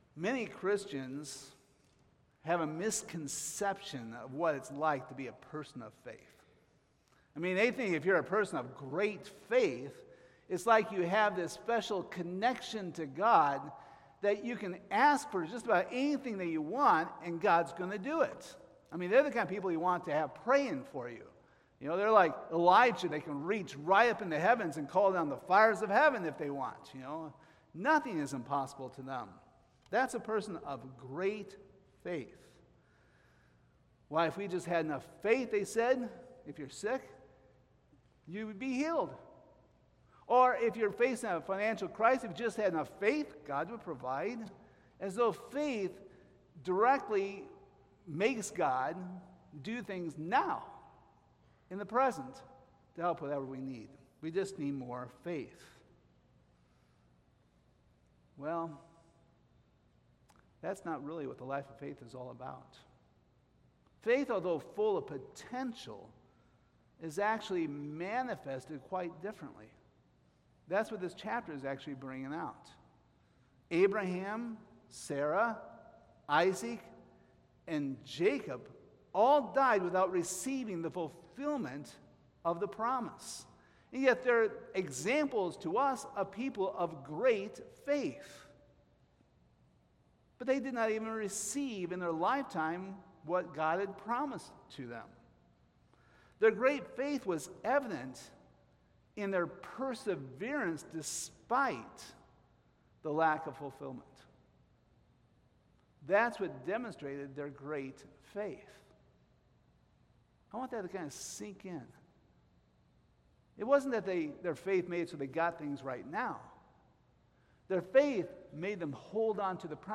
Hebrews 11:13-16 Service Type: Sunday Morning Sometimes we are tempted to think we can barter with God.